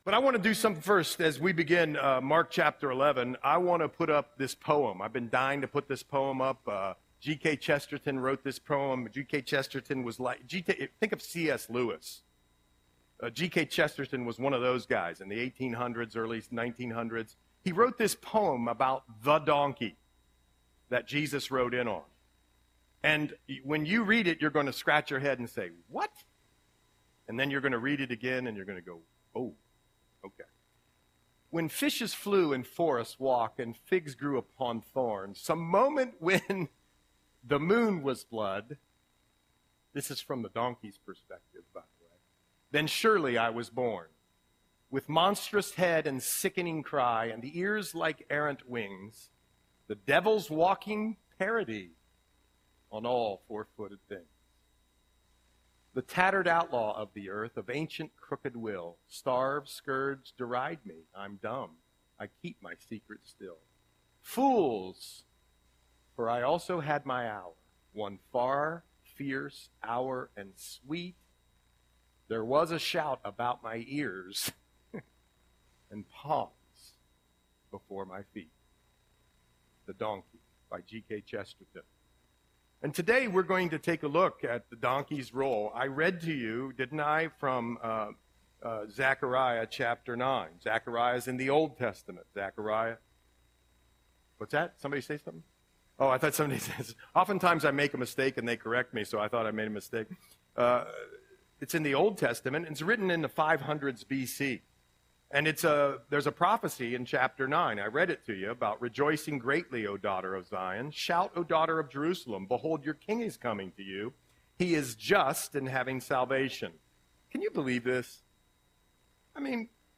Audio Sermon - February 9, 2025